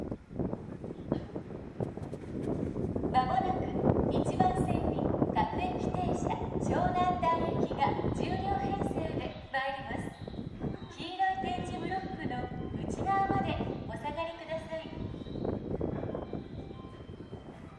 １番線SO：相鉄線
接近放送各駅停車　湘南台行き接近放送です。